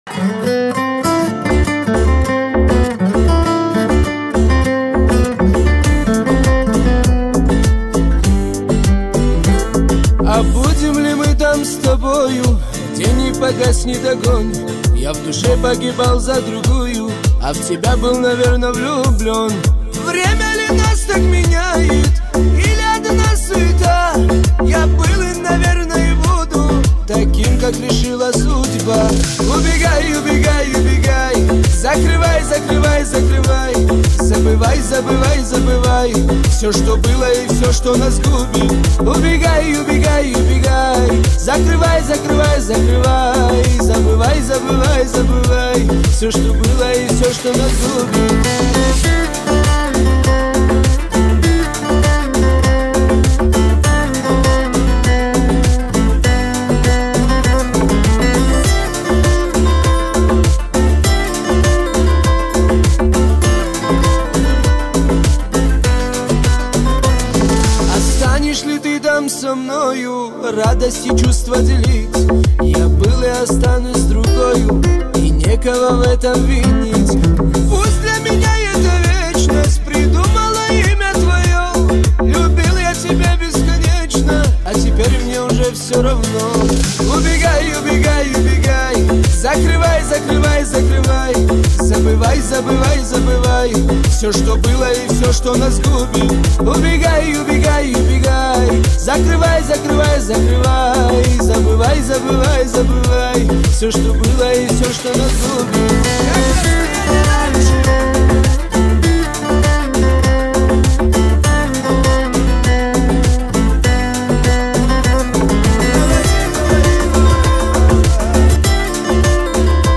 Кавказские песни